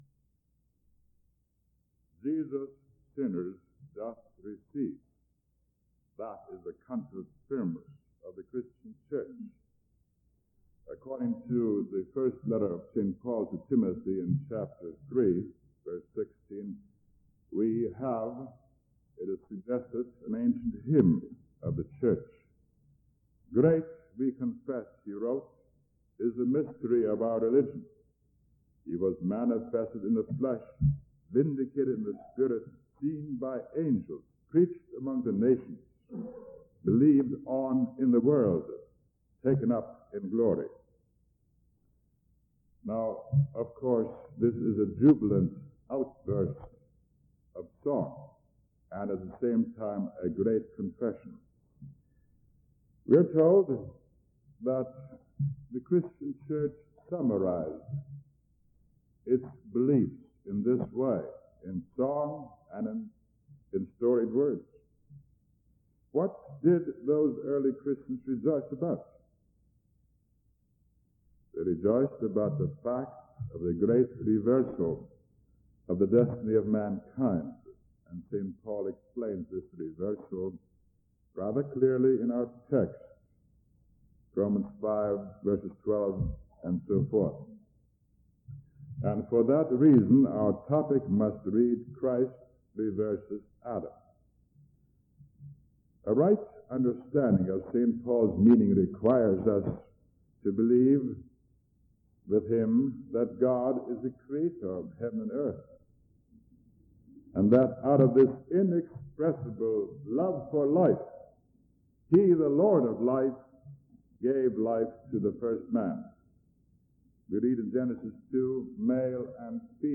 Chapel Sermons Academic Year 1976-1977 | Chapel Sermons | Concordia Seminary - Saint Louis